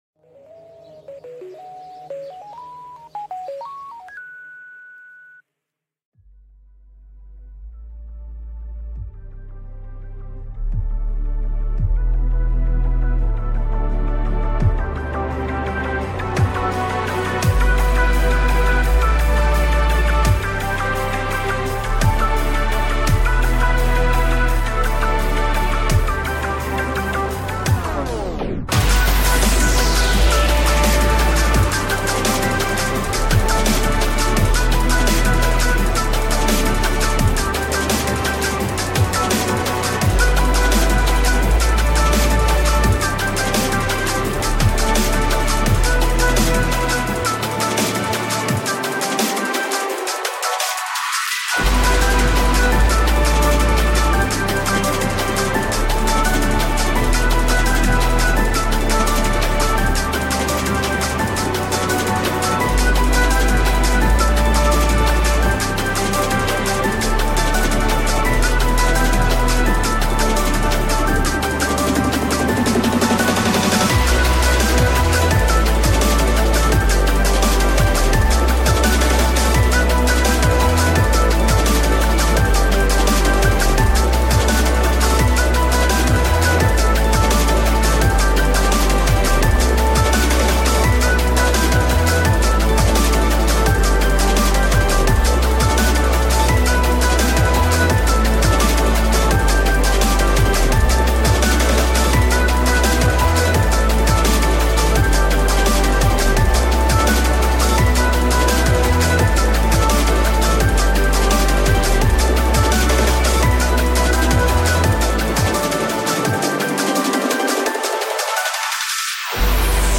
dnb